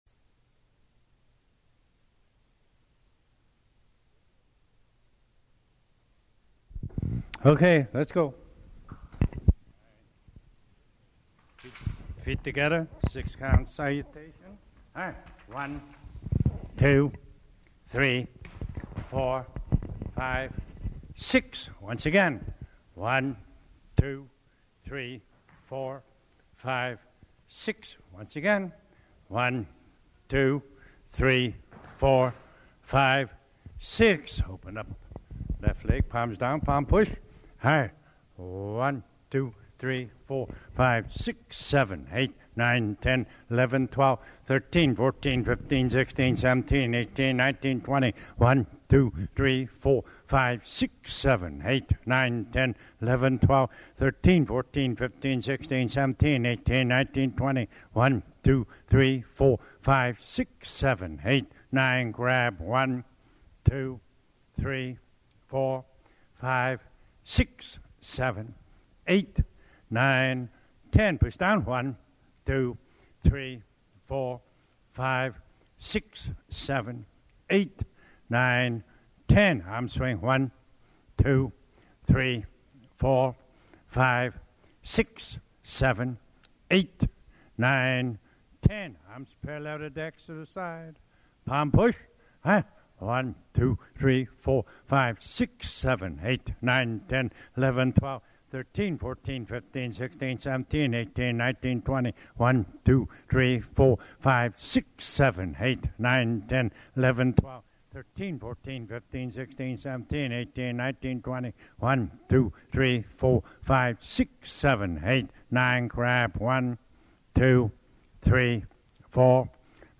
calling out the prompts for the 30 minute Kung Fu warm up.